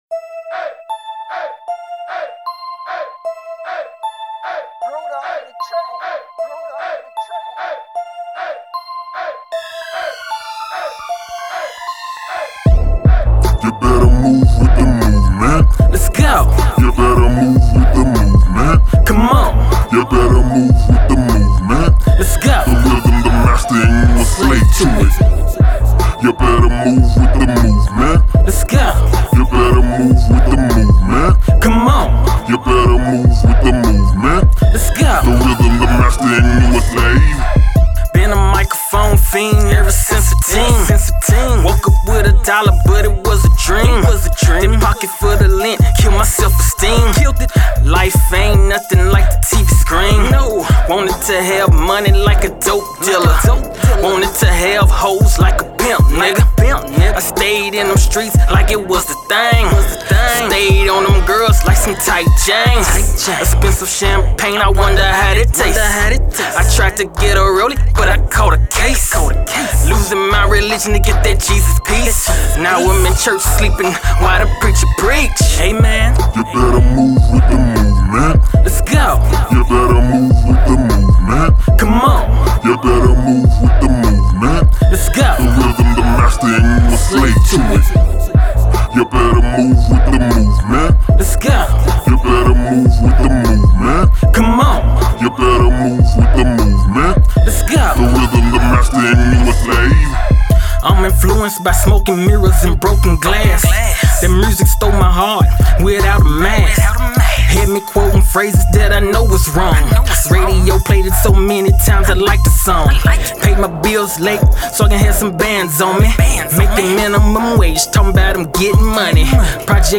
Hiphop
Hip Hop track